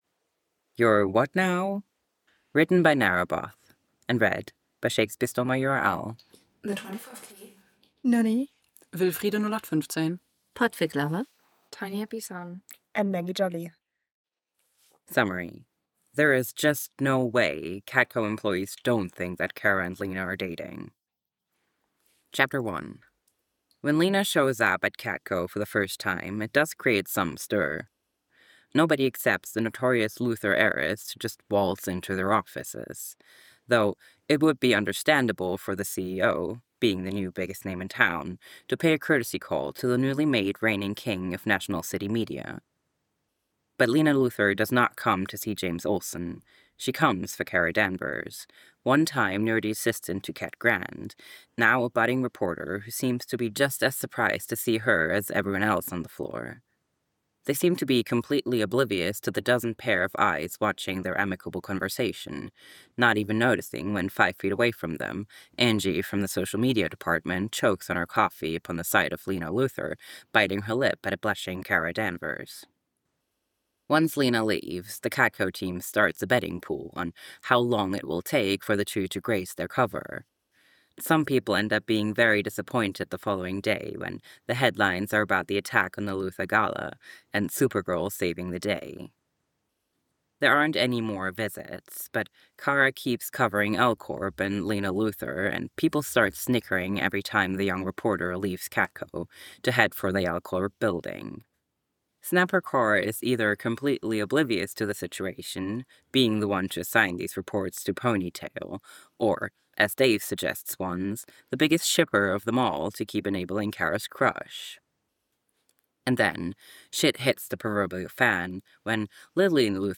info: collaboration|ensemble